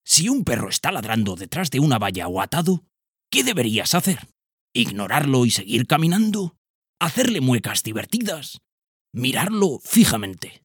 TEST PERROS DESCONOCIDOS-Narrador-06_0.mp3